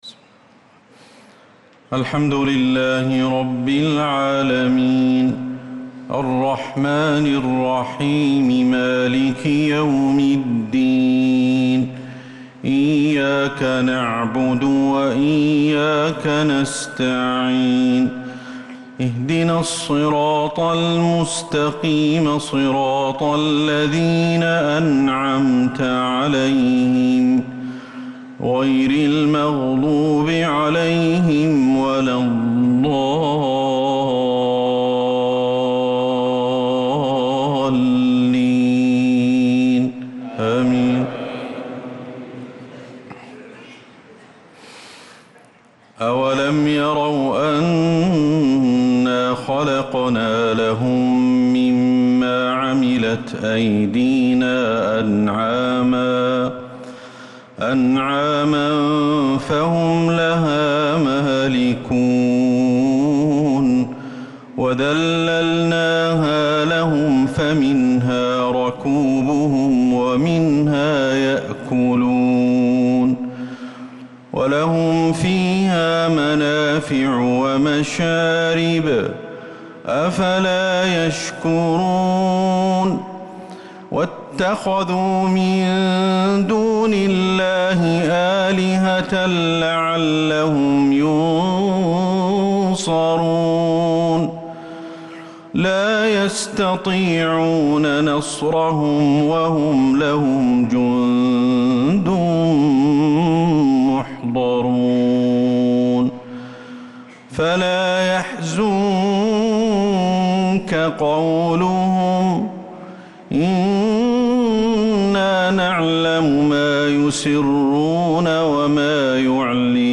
صلاة العشاء للقارئ أحمد الحذيفي 25 ذو الحجة 1445 هـ
تِلَاوَات الْحَرَمَيْن .